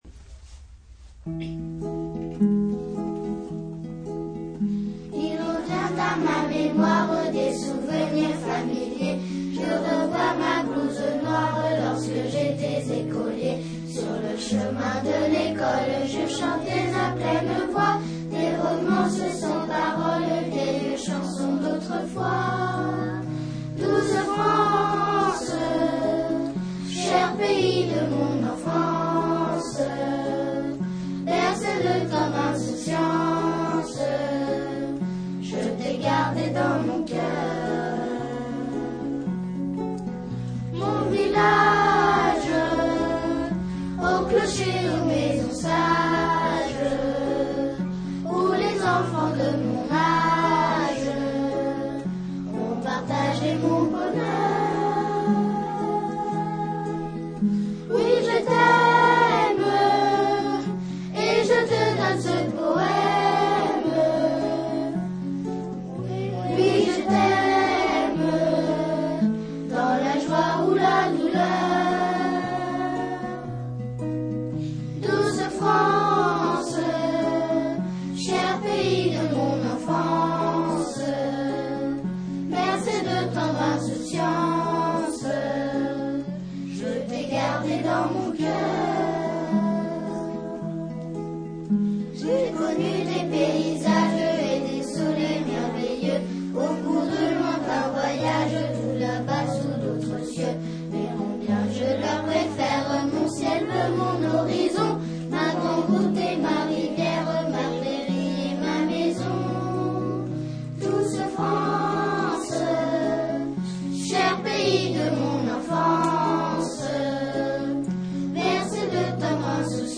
par Enfants de Houdon
L’école a donc organisé une session, vendredi soir, pour les parents.